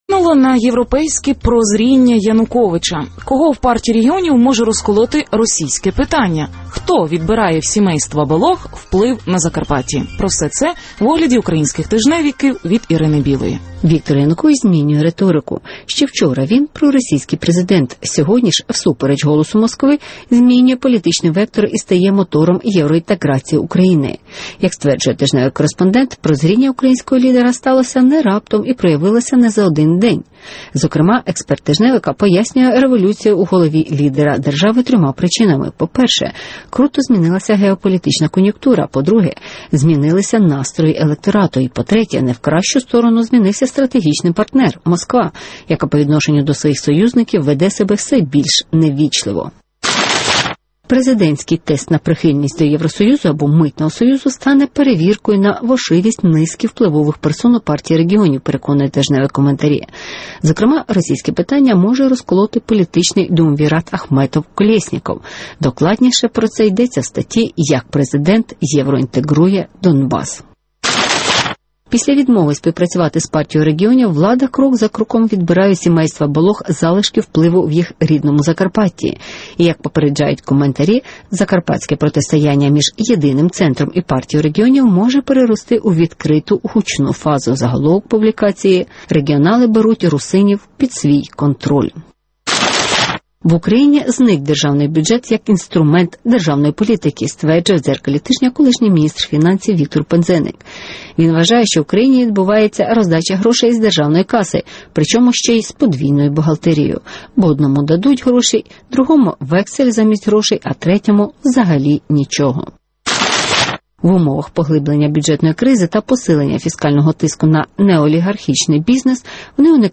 Що вплинуло на «європейське» прозріння Януковича? (Огляд преси)